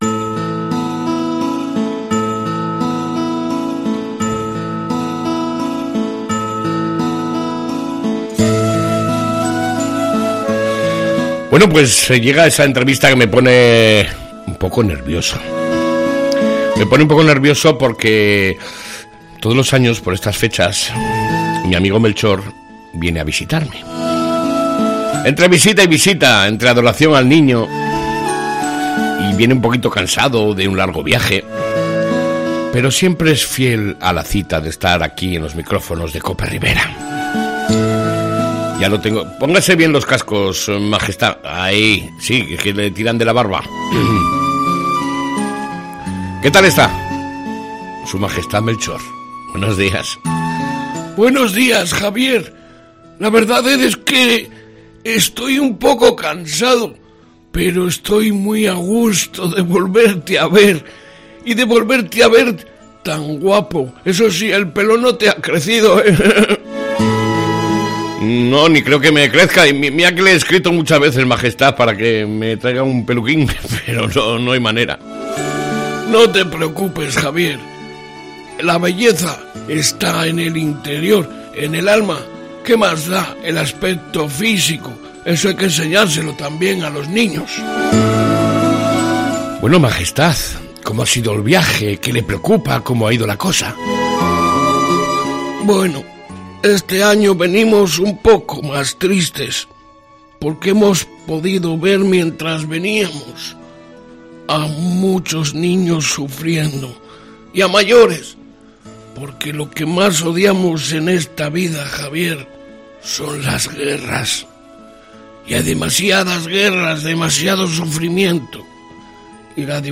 ENTREVISTA CON EL REY MELCHOR